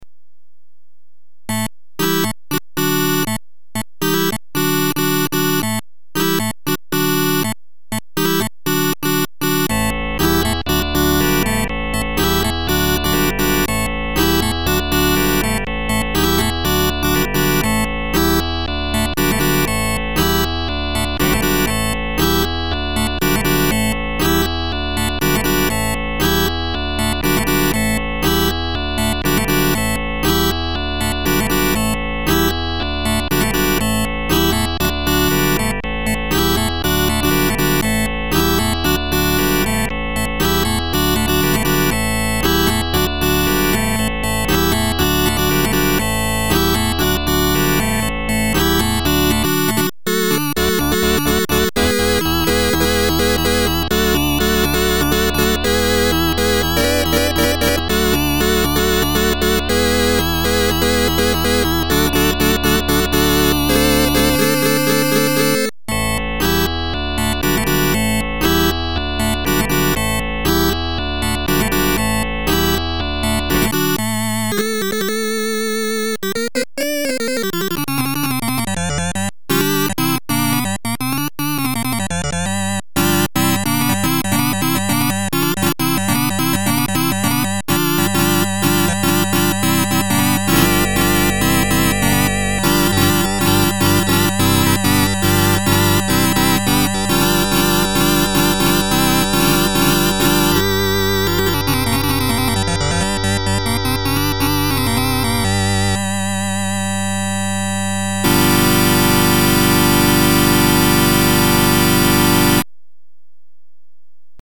lab Yamaha DX 7
sn-Vox Continental.mp3